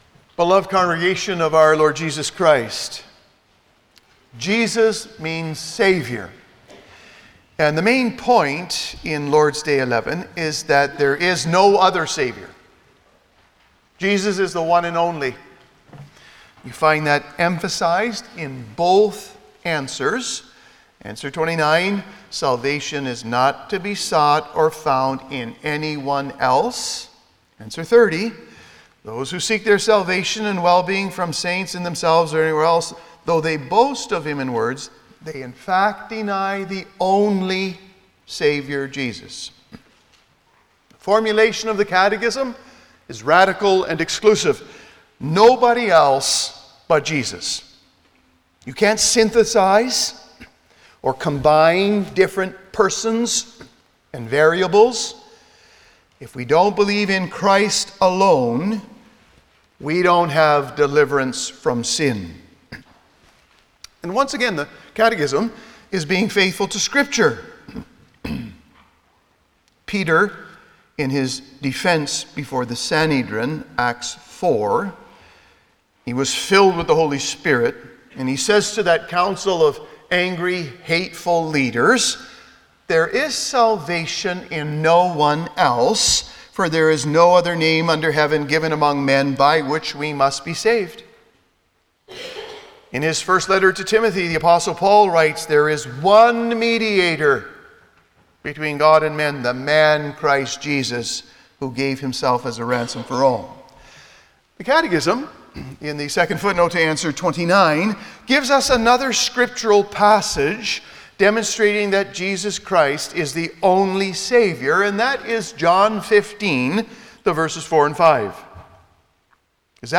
Service Type: Sunday afternoon
07-Sermon.mp3